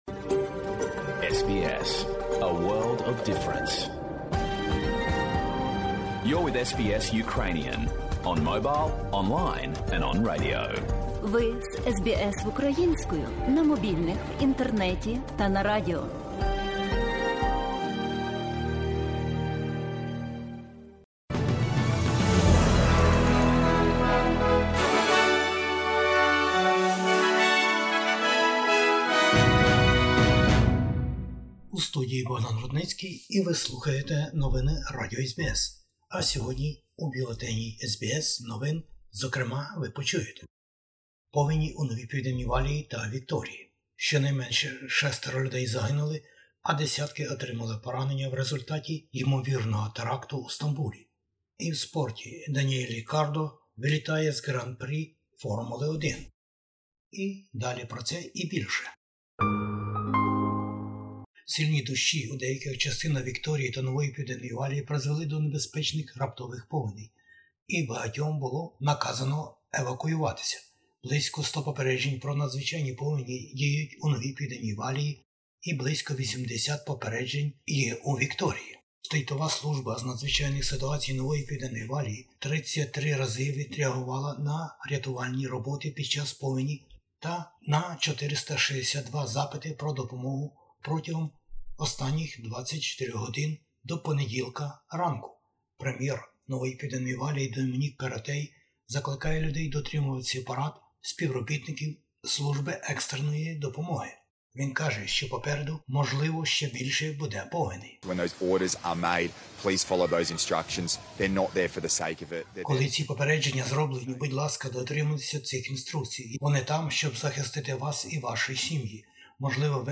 Бюлетень SBS новин - 14/11/2022